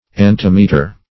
antimeter - definition of antimeter - synonyms, pronunciation, spelling from Free Dictionary
Search Result for " antimeter" : The Collaborative International Dictionary of English v.0.48: Antimeter \An*tim"e*ter\, n. [Gr.